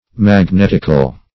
Magnetic \Mag*net"ic\, Magnetical \Mag*net"ic*al\, a. [L.